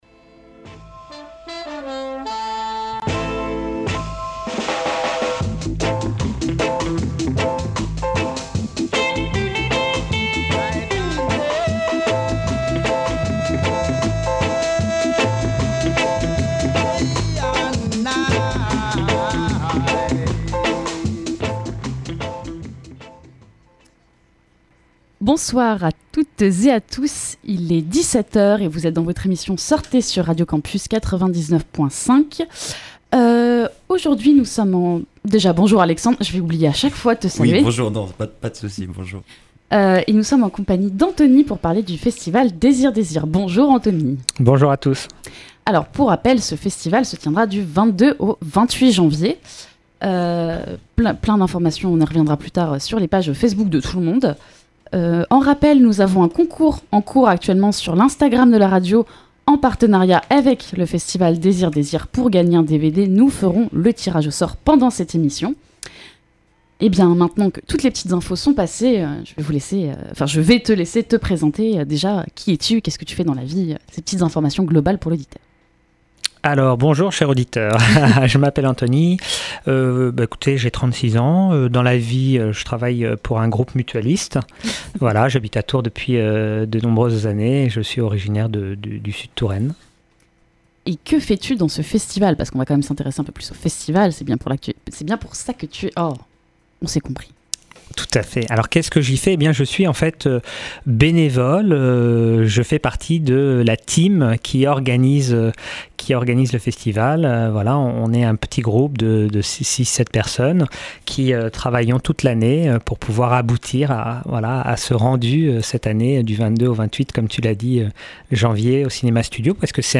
Notre invité est venu nous présenter ce festival et sa 27ème édition sur la thématique de l’exode. Le festival de cinéma Désir… Désirs aborde les thématiques liées à l’orientation sexuelle, à l’identité de genre et la place des femmes dans la société.